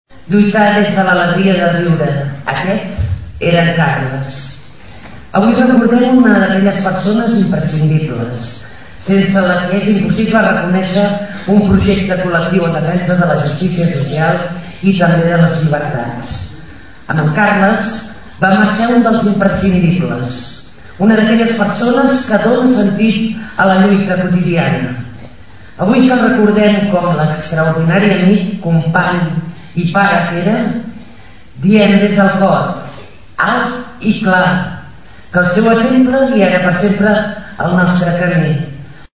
Va ser un acte senzill, però emotiu: diversos parlaments i algunes projeccions amb imatges de la seva vida política, acompanyades de música en directe, van servir per recordar-lo.
Isabel Roig, regidora d’ICV al Maresme, va llegir unes paraules en nom seu.